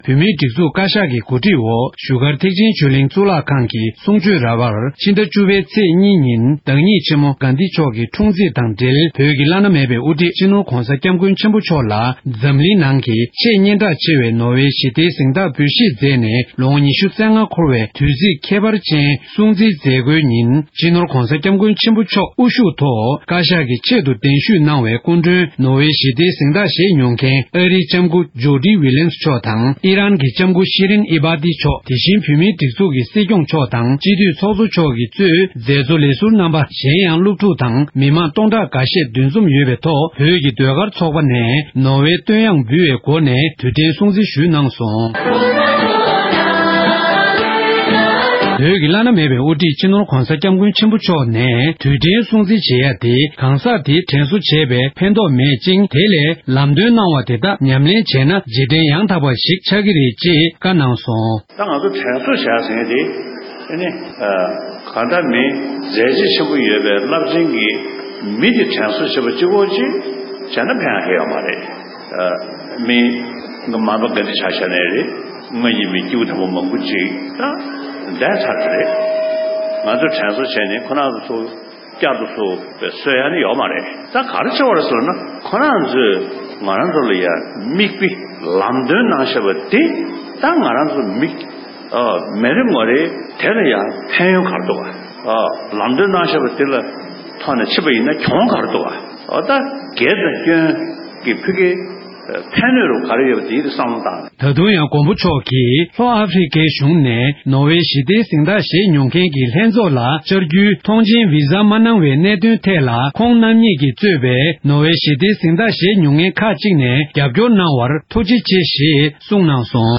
བཞུགས་སྒར་གཙུག་ལག་ཁང་དུ་དུས་དྲན་སྲུང་བརྩི་ཞུས་པ།
སྒྲ་ལྡན་གསར་འགྱུར། སྒྲ་ཕབ་ལེན།